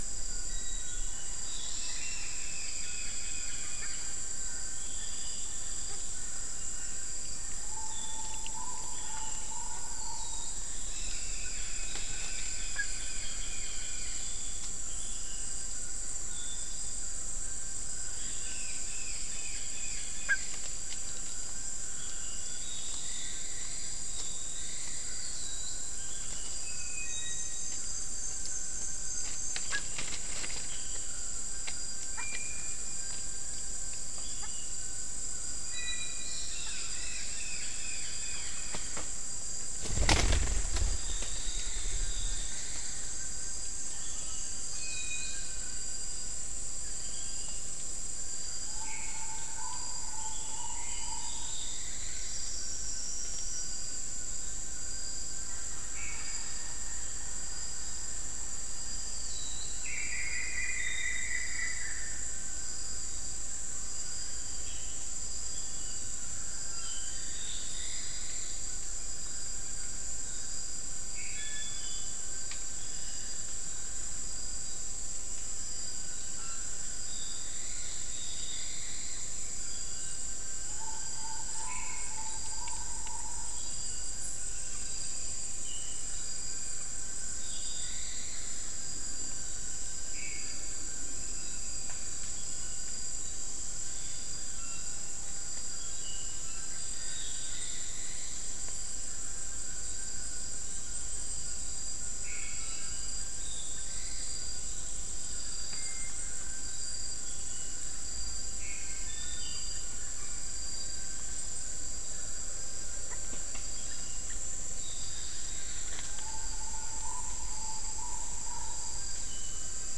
Non-specimen recording: Soundscape Recording Location: South America: Guyana: Sandstone: 4
Recorder: SM3